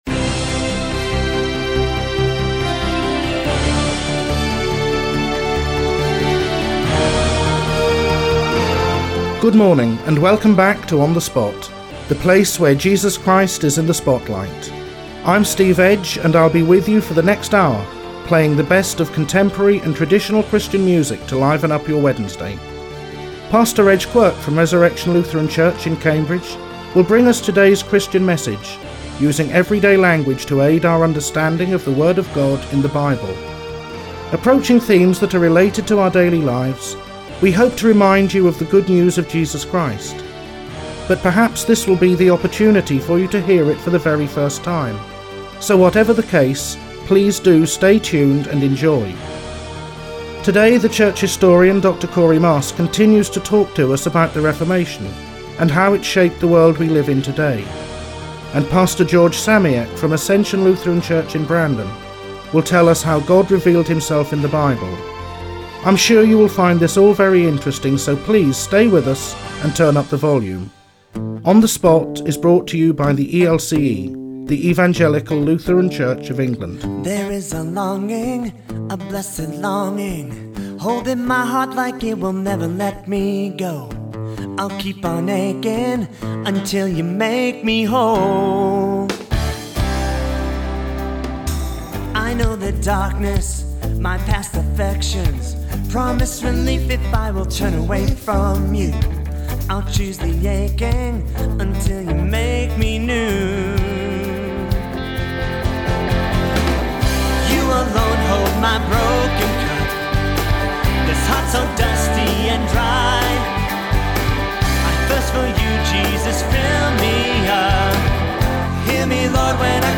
Rerun of series of six pilot-programs produced for Cambridge 105FM featuring the history of the Reformation, church practice, devotions in the light of contemporary issues as well as traditional and contemporary Christian songs.